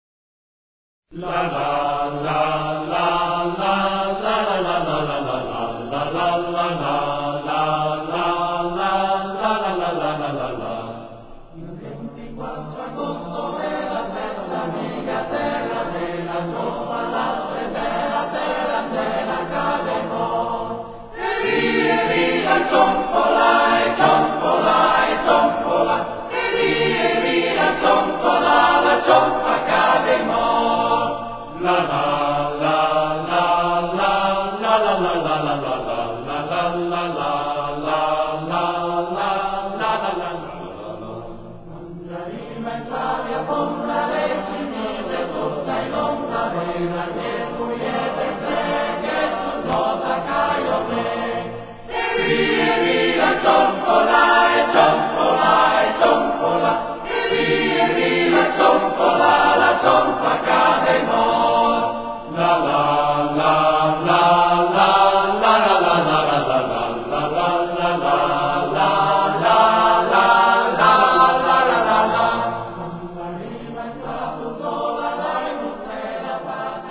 La Cionfa - Coro Stelutis